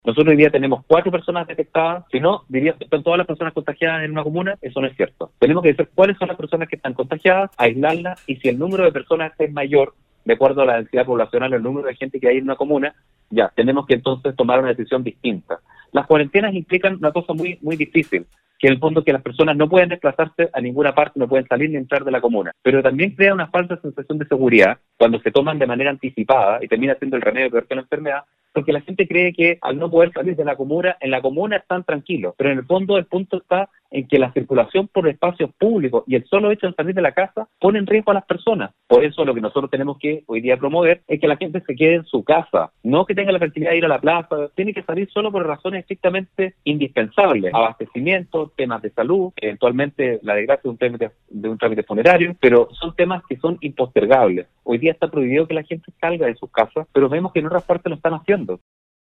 Ante la polémica que ha generado los cuatro casos que se han detectado en la comuna de Tierra Amarilla, el equipo de prensa de Nostálgica sostuvo un contacto con el Intendente de Atacama, Patricio Urquieta, quien se refirió a estos cuatro casos que corresponde a una misma familia, que viven en el mismo domicilio y el manejo de la información que siempre debe ser de la manera más responsable posible.